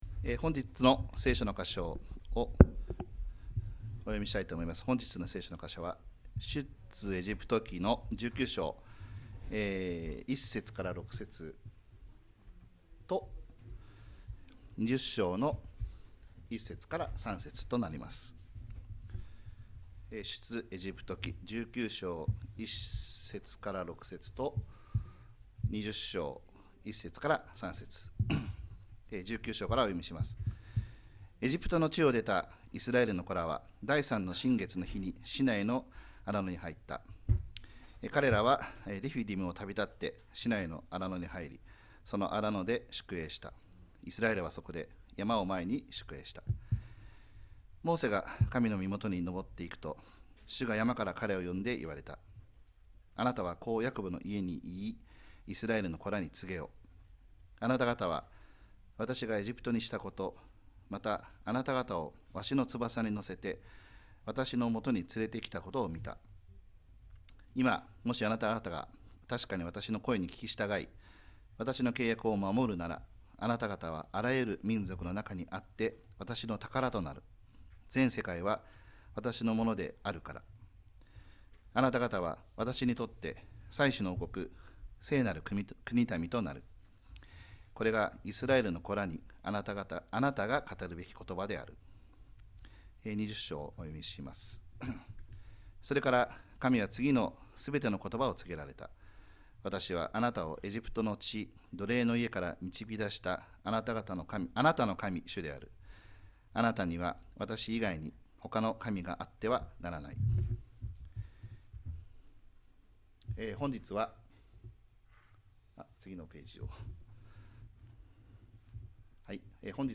2025年4月6日礼拝 説教 「『十戒』とキリスト」 – 海浜幕張めぐみ教会 – Kaihin Makuhari Grace Church